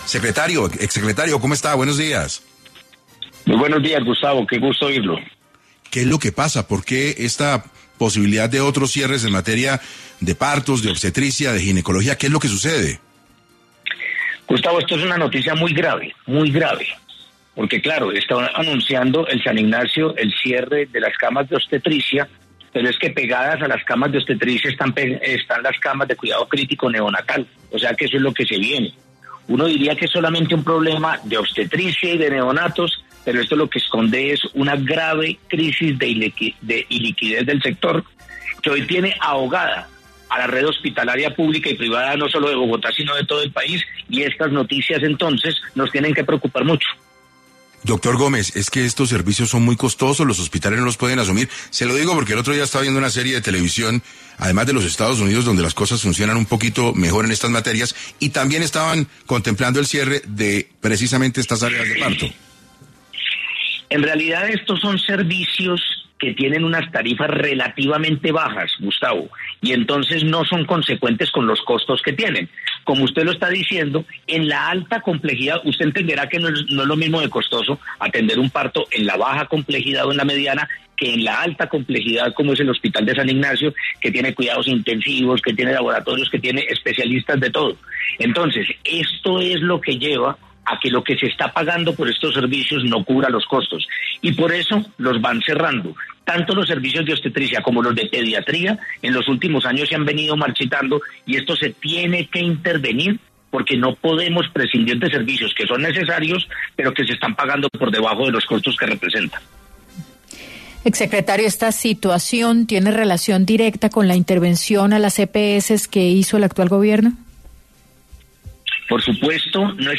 En 6AM de Caracol Radio estuvo Alejandro Gómez, ex secretario de Salud de Bogotá, quien hablo sobre por qué decidieron cerrar el servicio de parto en el Hospital Universitario San Ignacio.